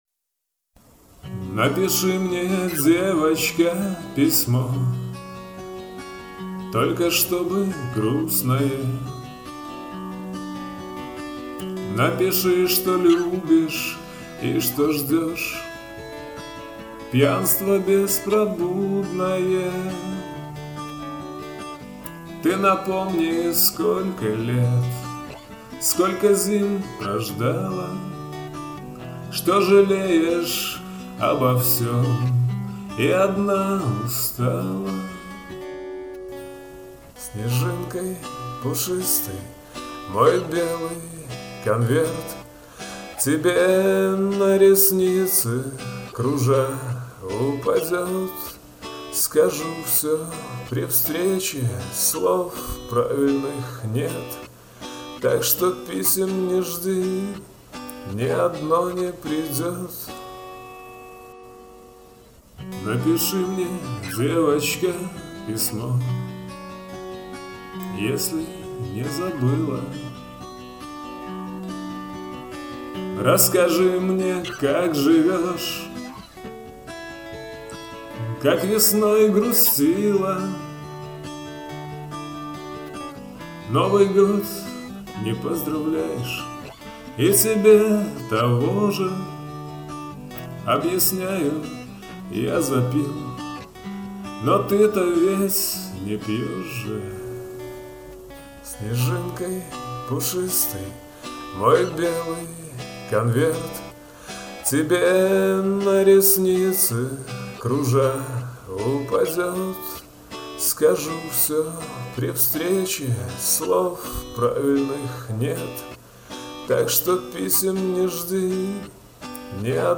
Лирические
Слушать на гитаре